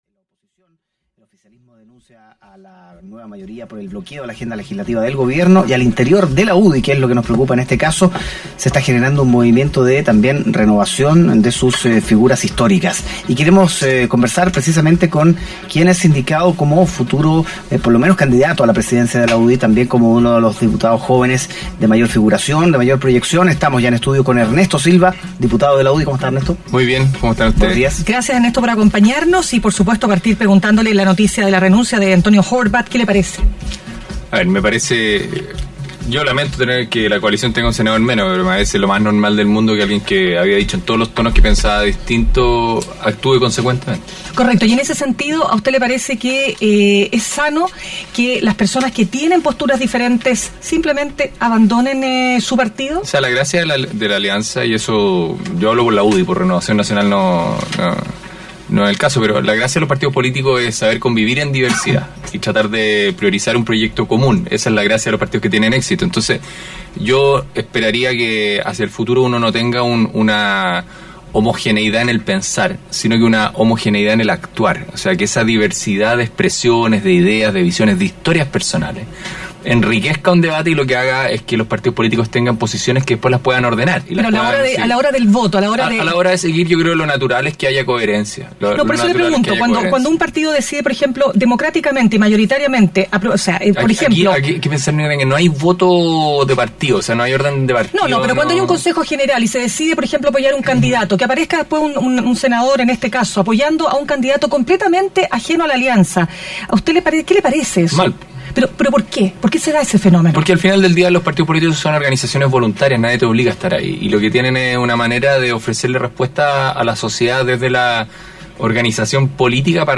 Hoy, en Mañana Será Otro Día conversamos con el diputado Ernesto Silva, sobre la renuncia de Antonio Horvarth a Renovación Nacional.